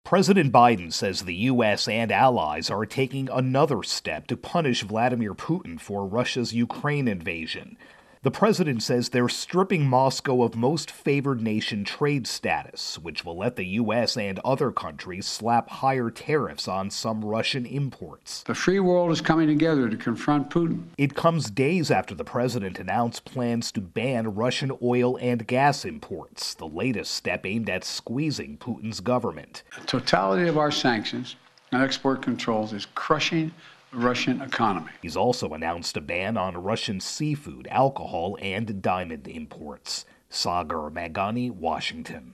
Russia-Ukraine War-Trade intro and wrap.